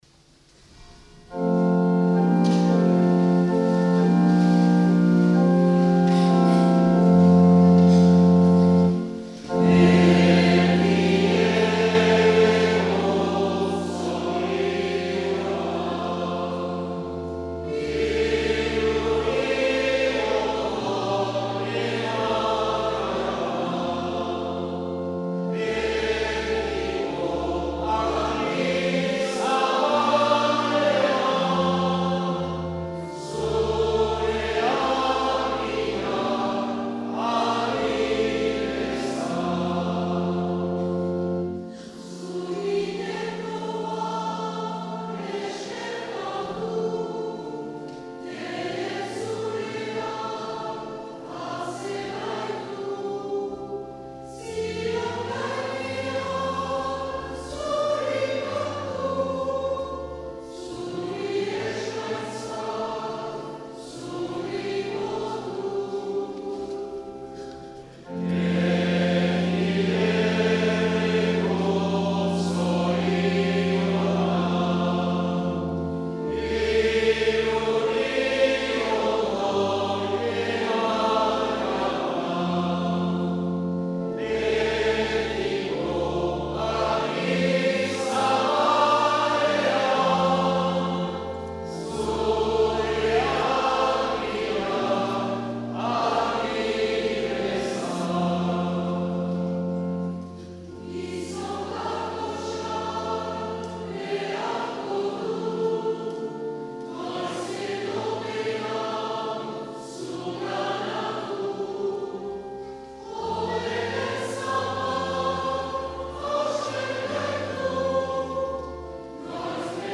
Azken agurra Ezpeletako elizan.
C’est à Espelette dans le cimetière de son village natal que le cardinal Etchegaray a été inhumé le 9 septembre 2019 après un temps de prière dans l’église de son baptême et de son ordination sacerdotale.